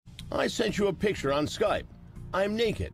Tags: chrishansen chris hansen voice